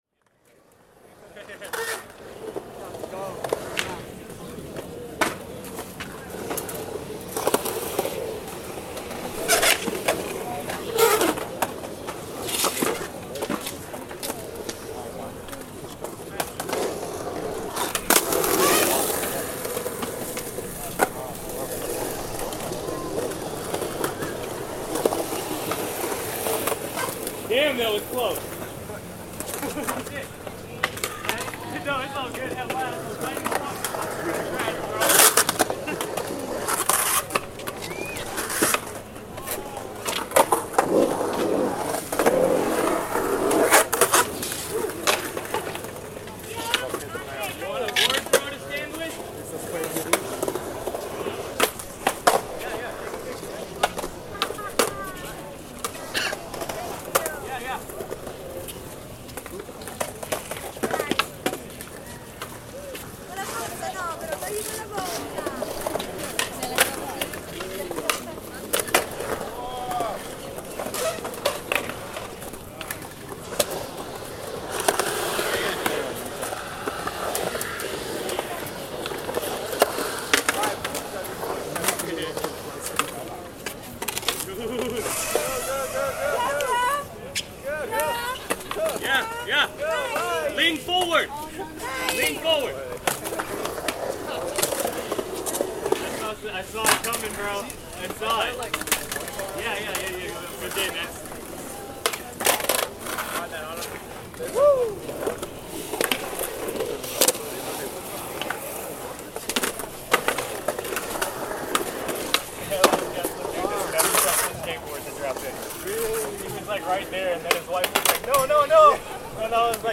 Skateboarding on Venice Beach